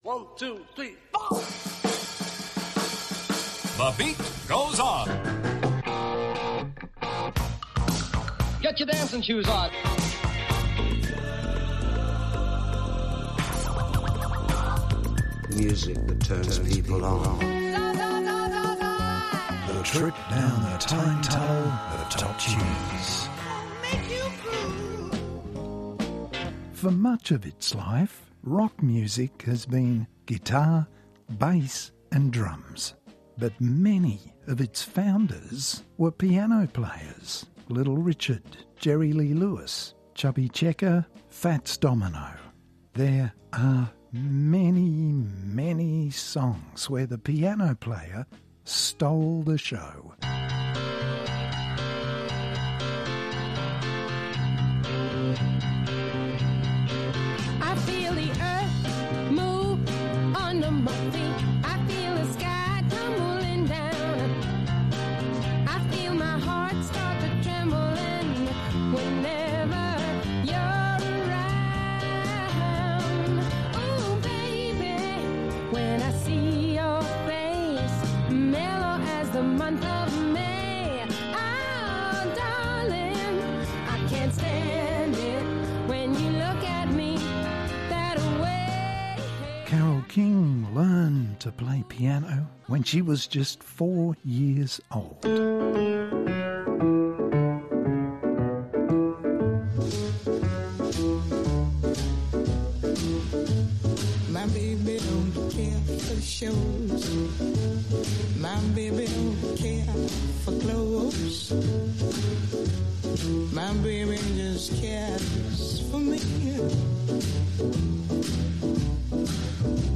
We look through the archives for songs where the piano part lifted the tune, where the keyboard player stole the show.